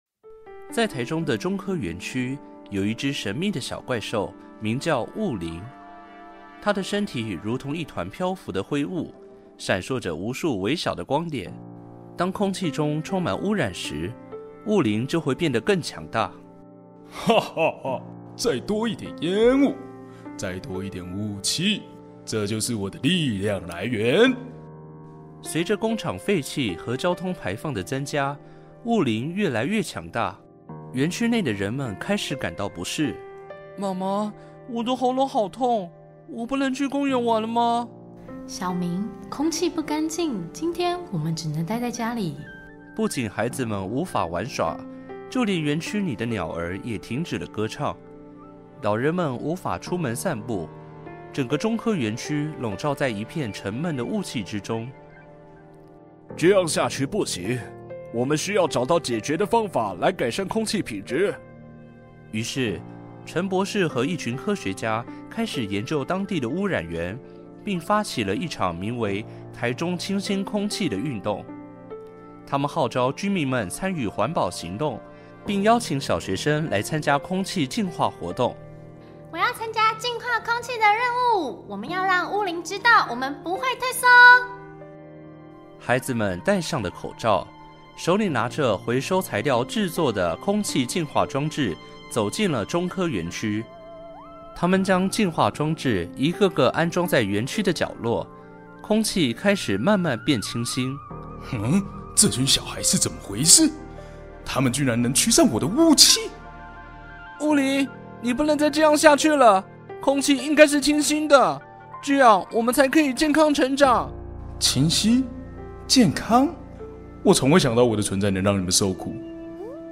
故事有聲書